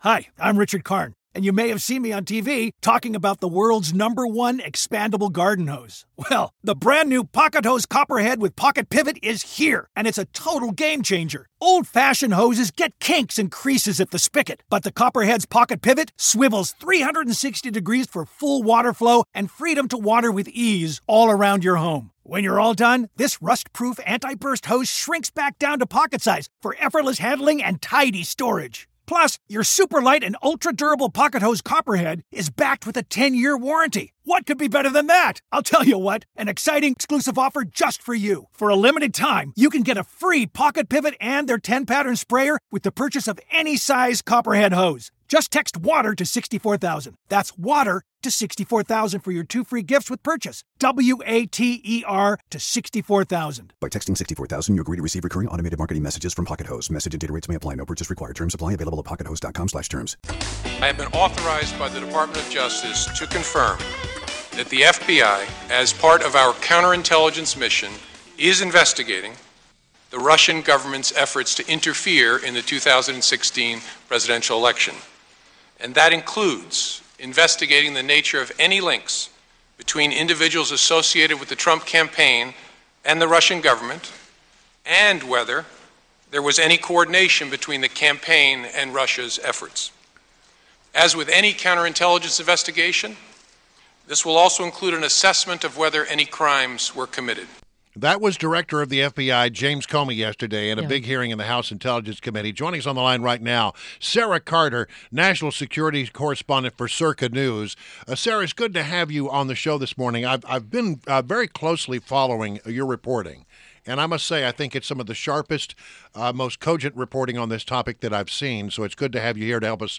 WMAL Interview - SARA CARTER - 03.21.17
INTERVIEW — SARA CARTER – National Security Correspondent at Circa News – analyzed the Comey hearing yesterday.